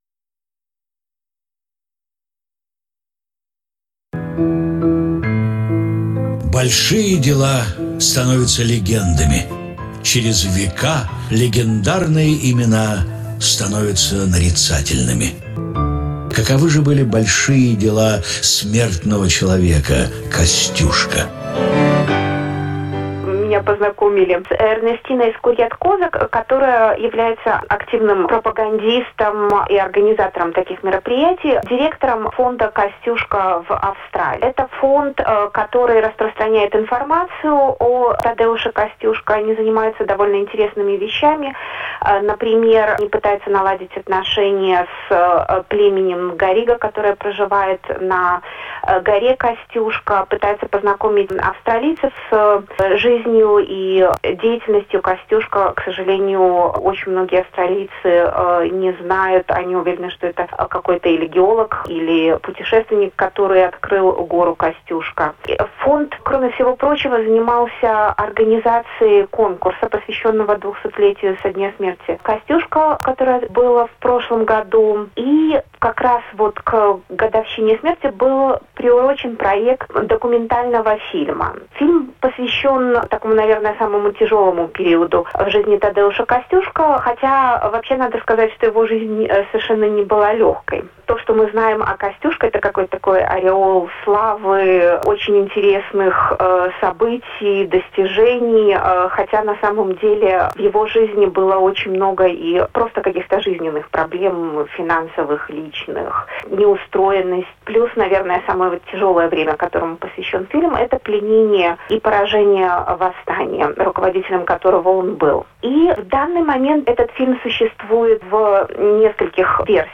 Na stronie internetowej Programu Rosyjskiego Radia SBS zamieszczono wywiad z członkiem "Kosciuszko Heritage Inc."